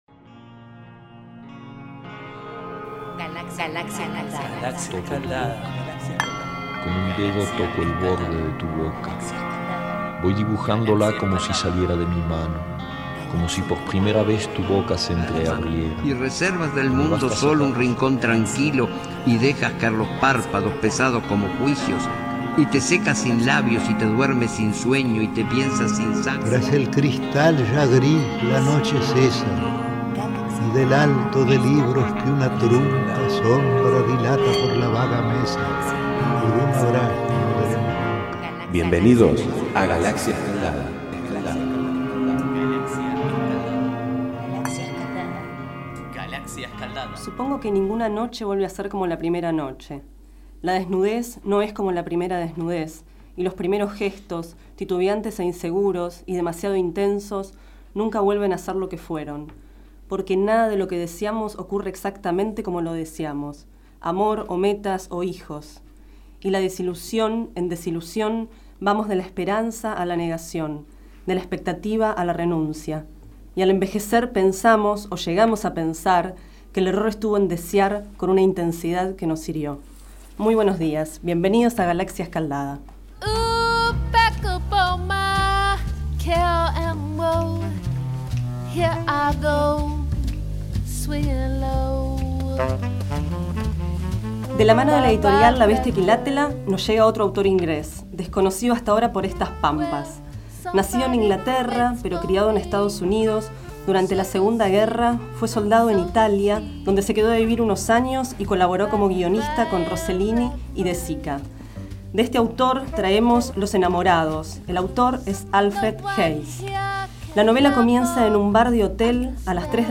24º micro radial, realizado el 18 de agosto de 2012, sobre el libro Los enamorados, de Alfred Hayes.